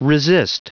Prononciation du mot resist en anglais (fichier audio)
Prononciation du mot : resist